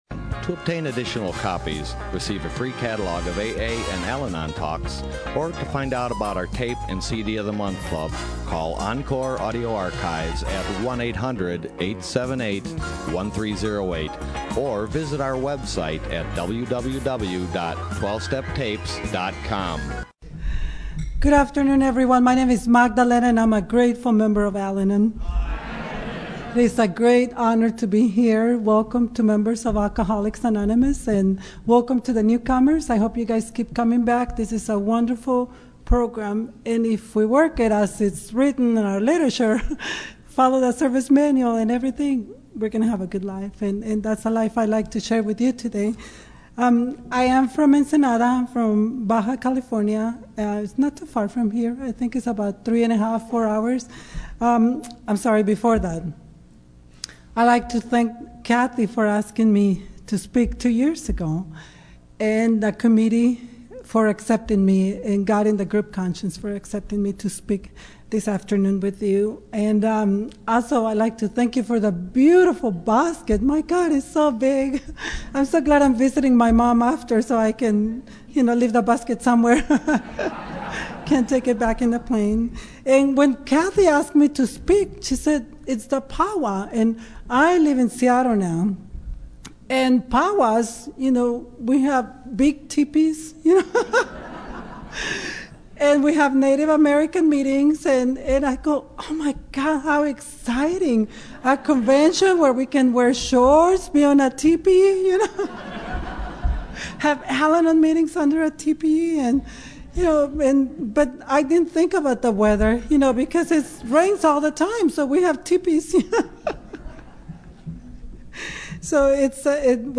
Desert POW WOW 2014